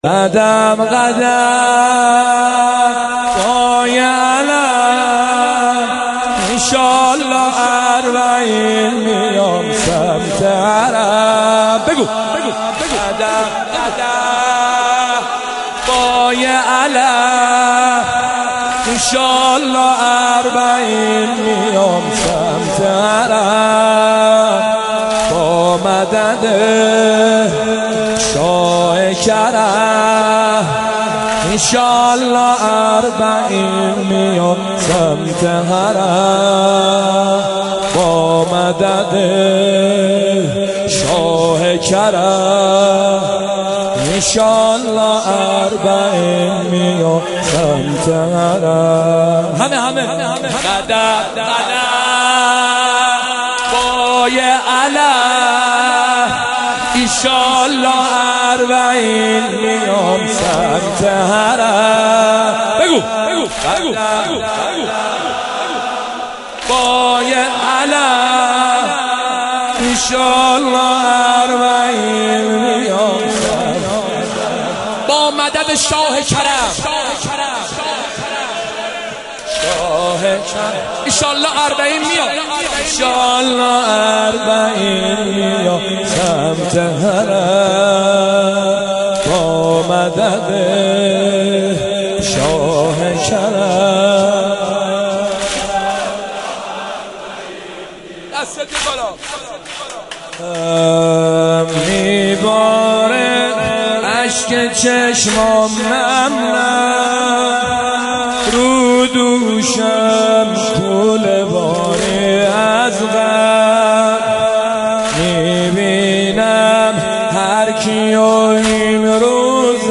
مداحی ویژه پیاده روی اربعین